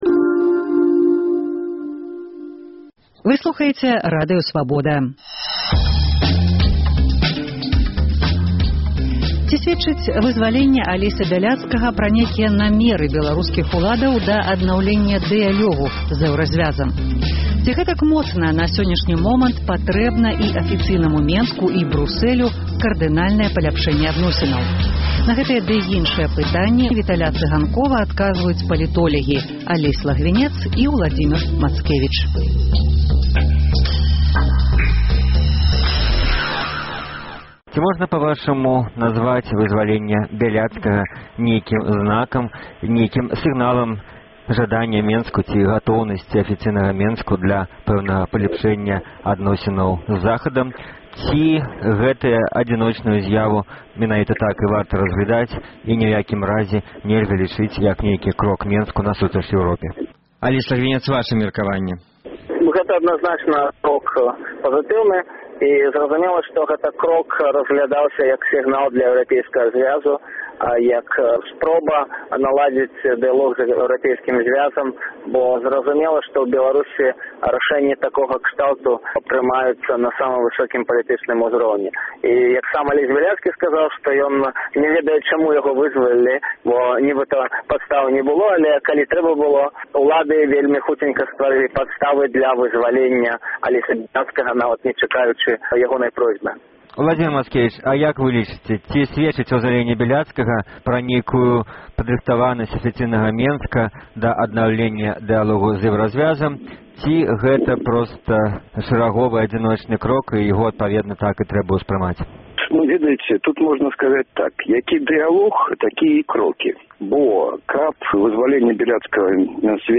Ці сьведчыць вызваленьне Алеся Бяляцкага пра намеры беларускіх уладаў да аднаўленьня дыялёгу з Эўразьвязам? Ці гэтак моцна на сёньняшні момант патрэбна і афіцыйнаму Менску, і Брусэлю кардынальнае паляпшэньне адносінаў? На гэтыя ды іншыя пытаньні ў перадачы Экспэртыза Свабоды адказваюць палітолягі